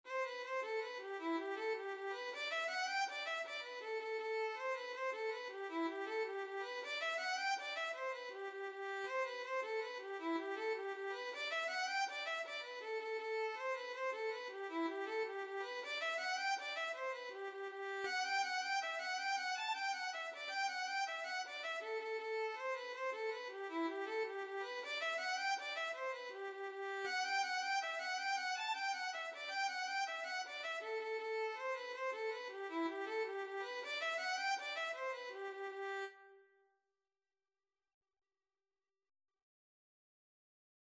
Violin version
G major (Sounding Pitch) (View more G major Music for Violin )
6/8 (View more 6/8 Music)
Violin  (View more Intermediate Violin Music)
Traditional (View more Traditional Violin Music)
Irish
kitty_come_over_ON778_vln.mp3